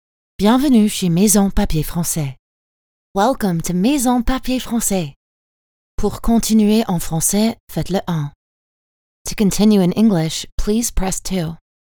Natural, Reliable, Warm, Distinctive, Versatile
Telephony